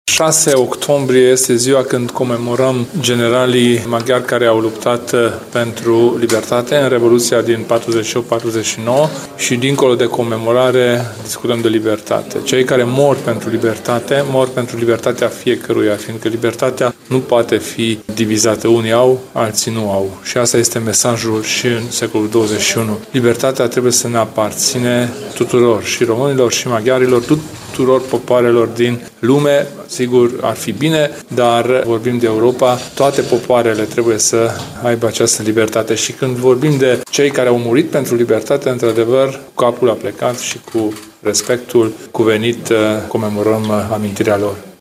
Libertatea trebuie să ne aparțină tuturor, a spus, astăzi, președintele UDMR, Kelemen Hunor, la Arad.
Liderul UDMR a participat la comemorările dedicate memorie celor 13 generali executaţi la sfârşitul Revoluţiei de la 1848.